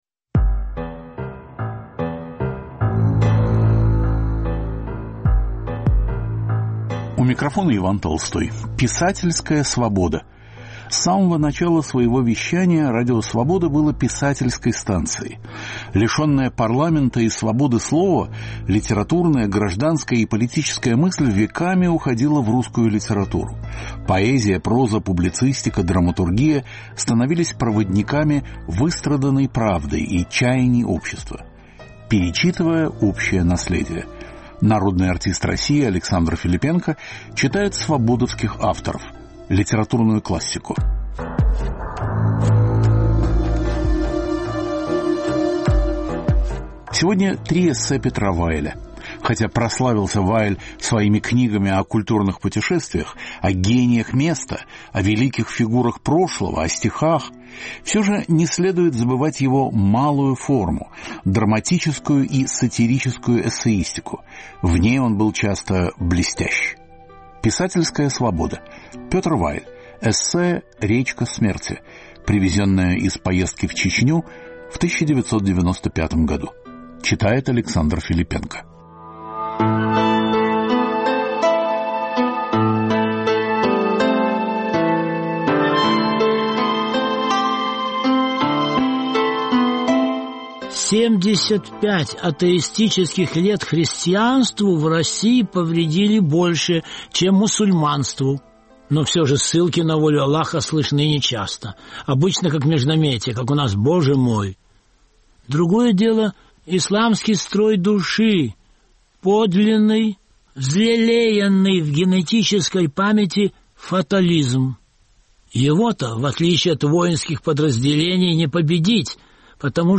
Писательская свобода. Народный артист России Александр Филиппенко читает авторов Радио Свобода. Звучат три эссе Петра Вайля
В чтении А. Филиппенко прозвучат три коротких очерка.